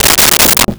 Desk Drawer Close 01
Desk Drawer Close 01.wav